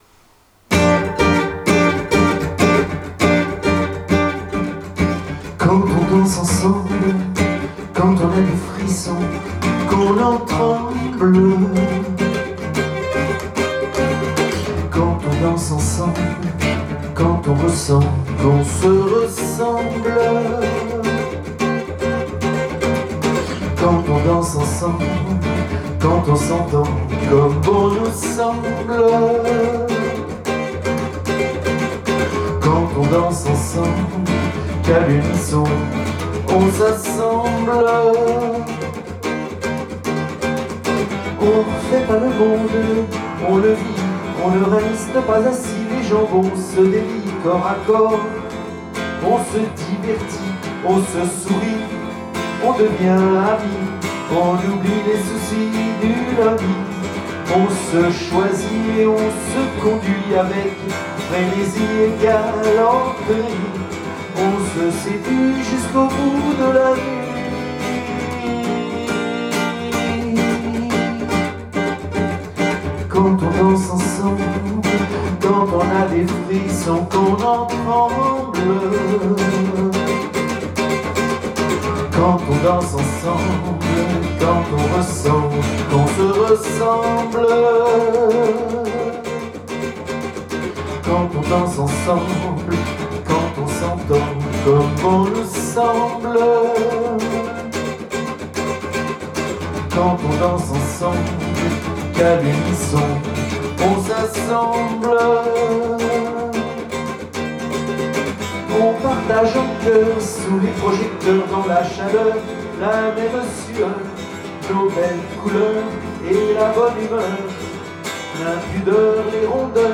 Show danse alternant samba et valse :